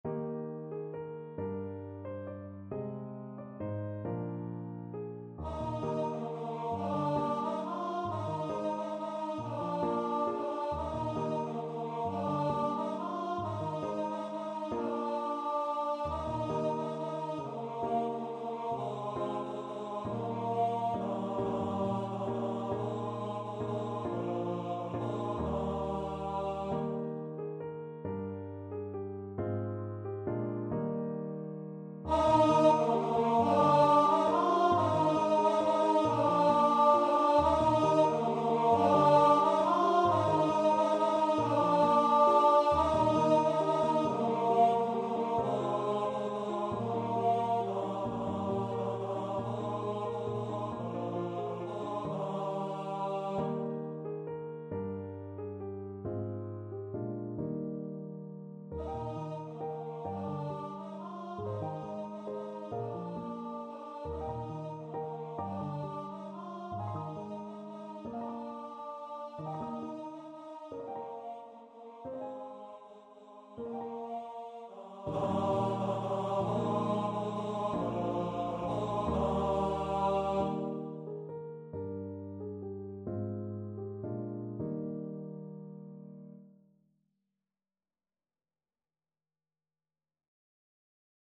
Free Sheet music for Choir (SATB)
.=45 Gently Lilting .=c.45
6/8 (View more 6/8 Music)